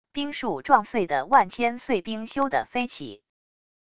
冰树撞碎的万千碎冰咻地飞起wav音频